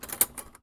R - Foley 151.wav